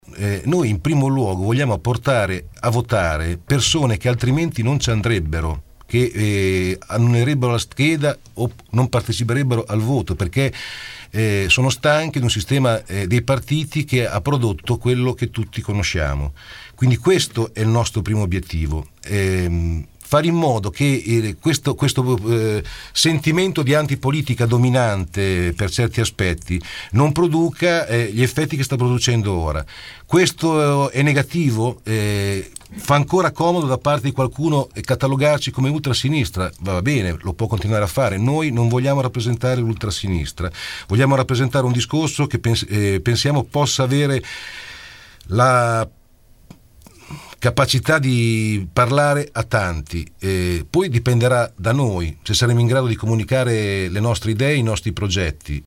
Con questa battuta al veleno nei confronti di Alfredo Cazzola e della sua iniziativa al cinema Galliera, Valerio Monteventi inaugura la sua campagna elettorale, ospite questa mattina nei nostri studi all’interno di Angolo B.